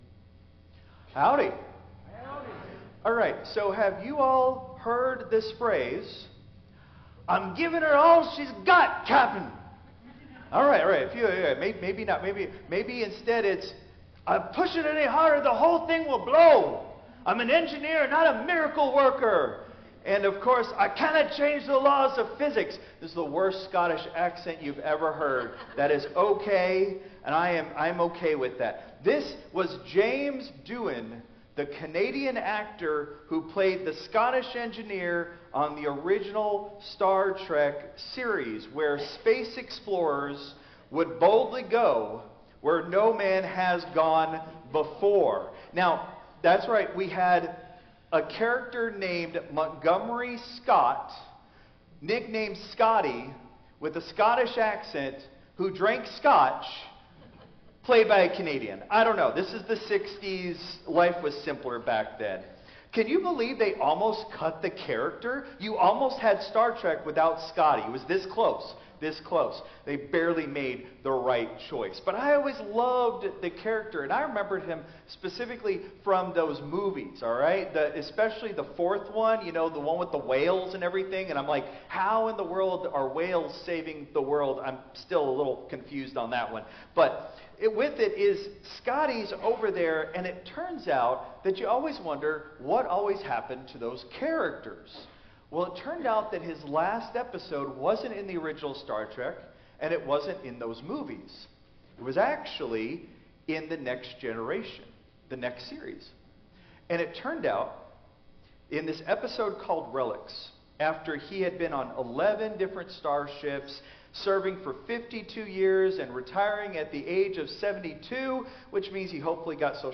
Christ Memorial Lutheran Church - Houston TX - CMLC 2025-03-02 Sermon (Contemporary)